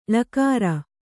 ♪ ḷakāra